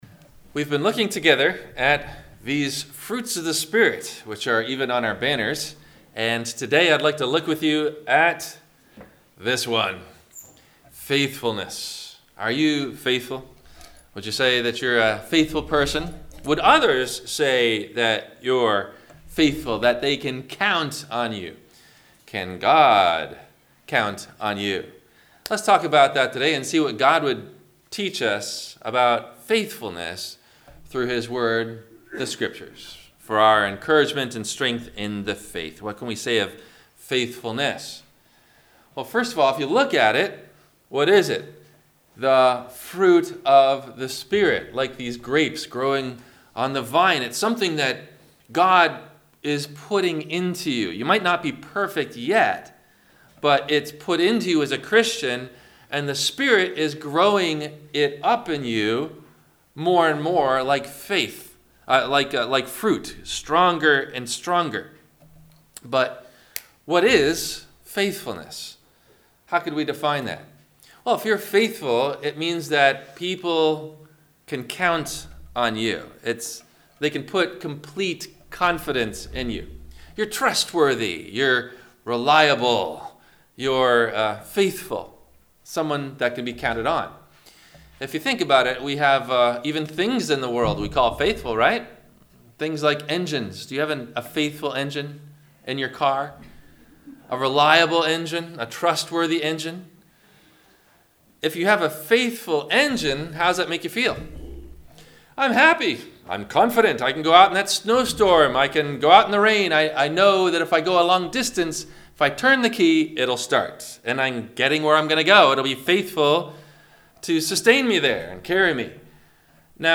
- Sermon - November 17 2019 - Christ Lutheran Cape Canaveral